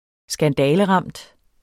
Udtale [ -ˌʁɑmˀd ]